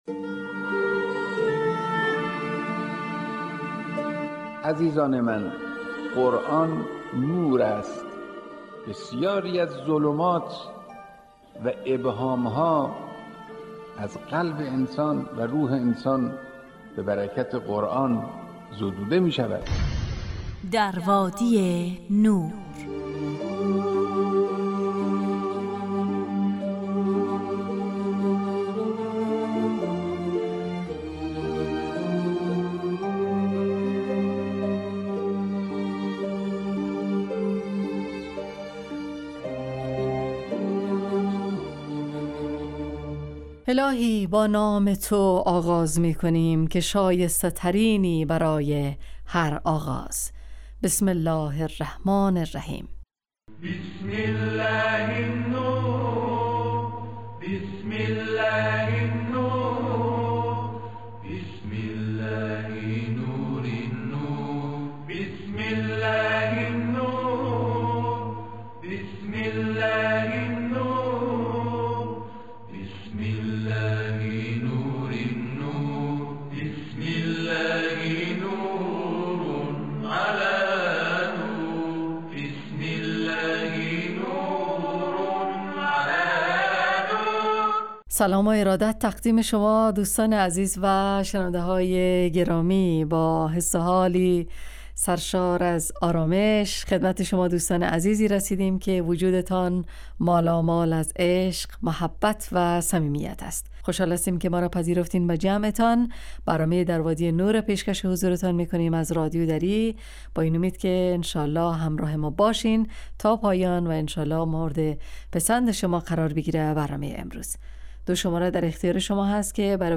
روزهای زوج: ( واژه های نور، اسماء الهی، ایستگاه تلاوت، داستان آیات، تفسیر روان و آموزه های زندگی ساز.